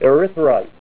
Help on Name Pronunciation: Name Pronunciation: Erythrite + Pronunciation